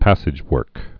(păsĭj-wûrk)